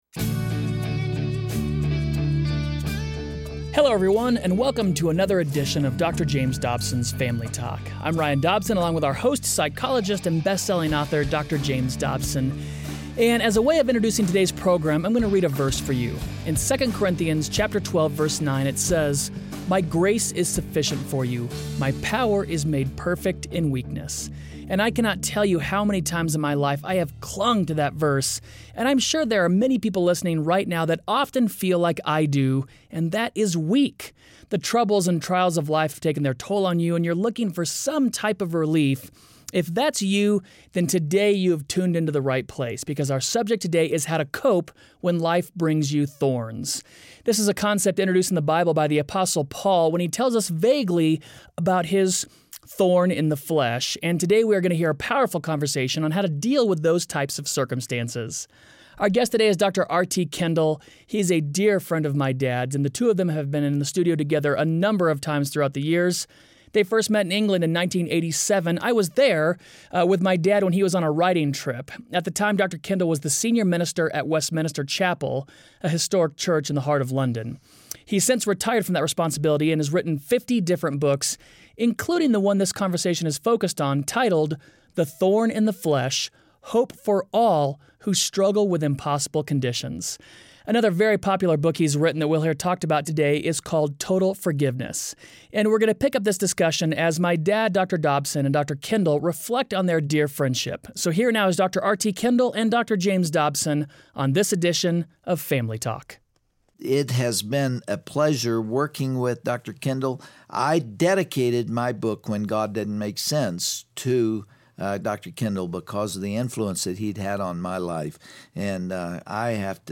Frustrations, depression, anxiety, and stress can all have a devastating effect on someone's life if they don't have the perspective to see what God is doing. Dr. Dobson will interview Dr. R.T. Kendall on how God often uses the darkest times in our lives for something good.